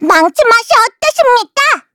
Taily-Vox_Skill4_kr_a.wav